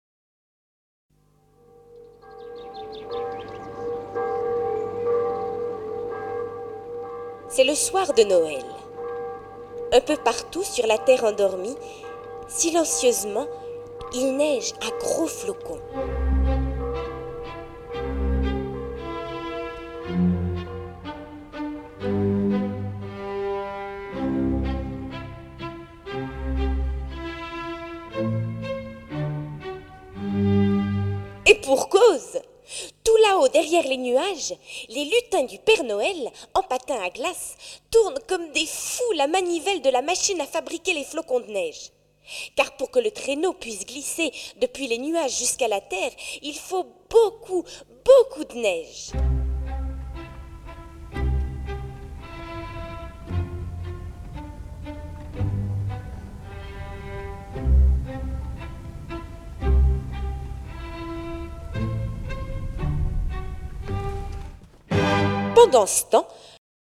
audio conte musical